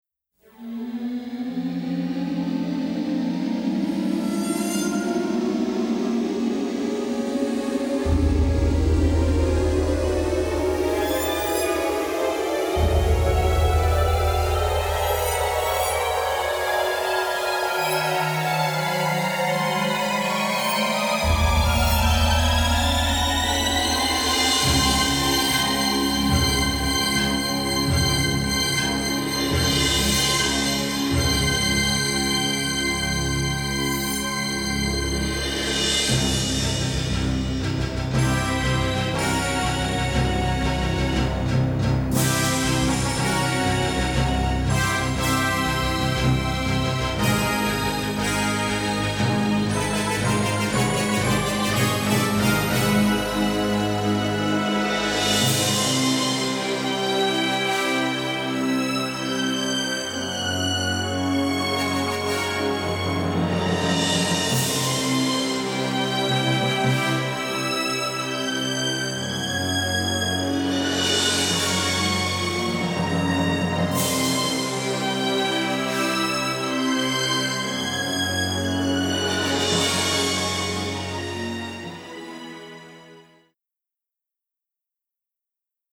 sci-fi soundtrack